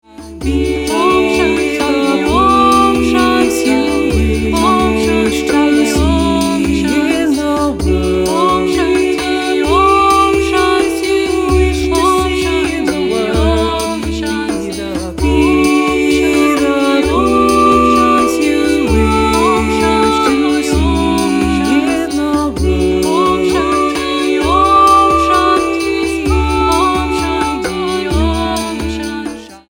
a joyful, prayerful romp for absolutely everybody.
A cappella